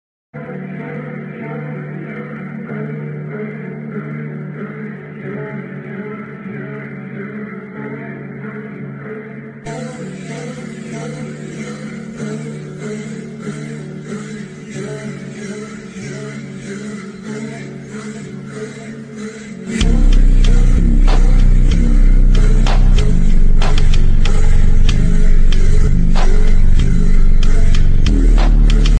⚠ Warning ⚠ Extreme bassbossted